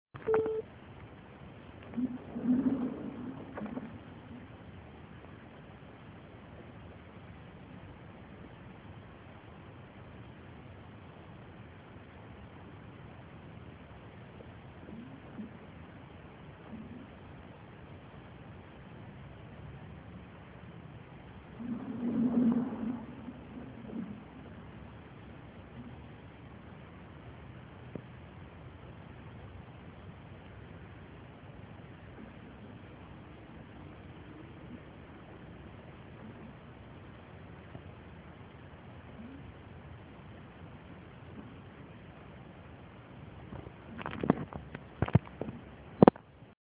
Solen skiner och isen sjunger
isen.mp3